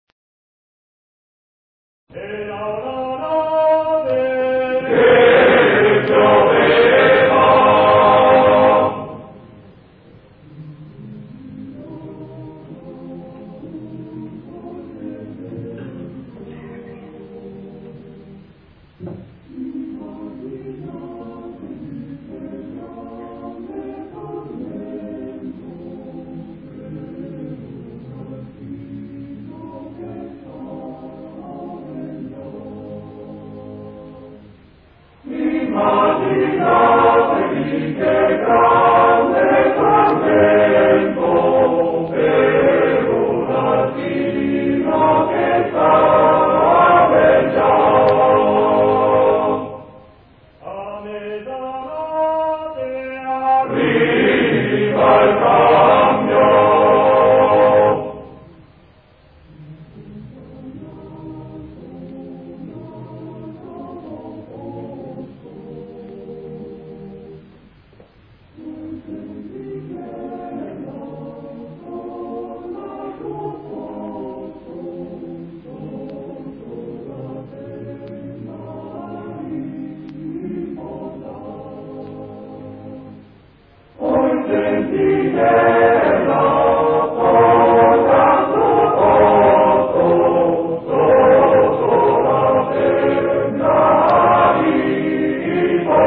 Ricerca, elaborazione, esecuzione di canti popolari emiliani
Canto goliardico di cui si hanno notizie fino dal XIII secolo.
Trascrizione per coro a voci miste di Giorgio Vacchi .
Canti Gaudeamus igitur 2:18 minutes (269.69 KB) voci: voci virili Gaudeamus igitur iuvenes dum sumus.